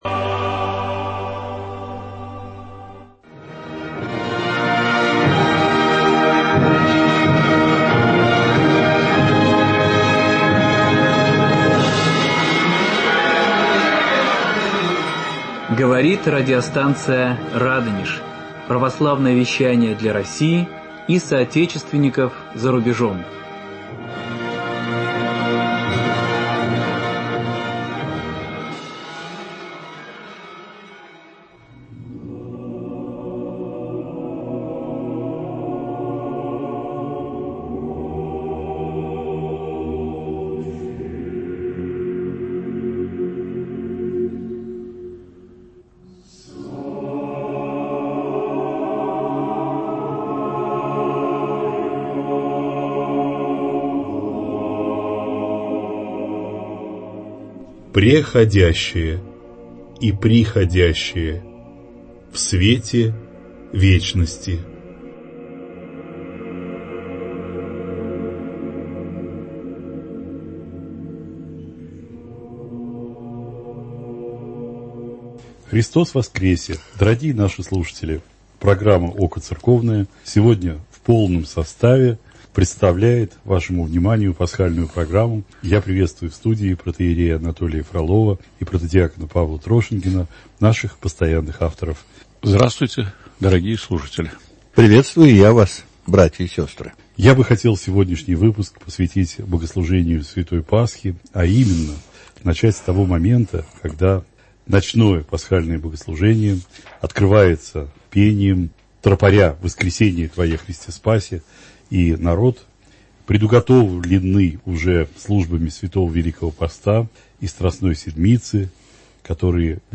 В пасхальном выпуске программы наши ведущие